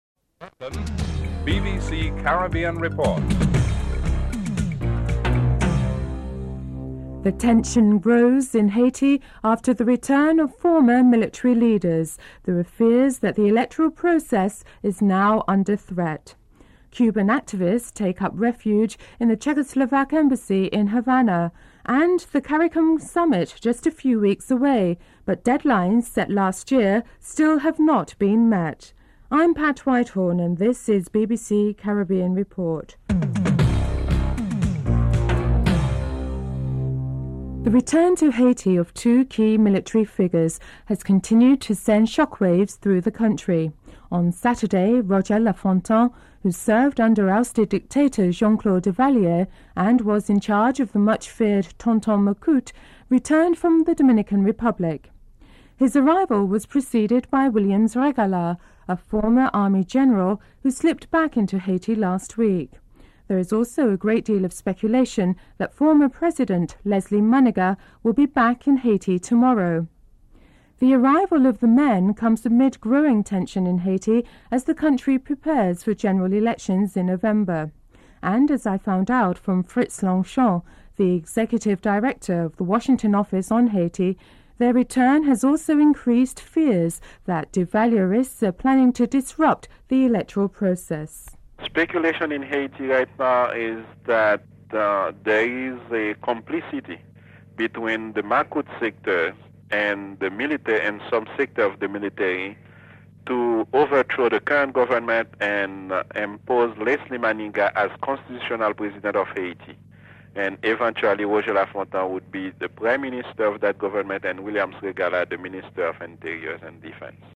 1. Headlines (00:00-00:36)
3. Financial News (05:45-06:44)